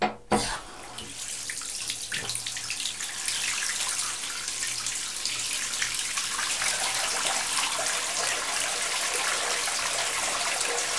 bath1.wav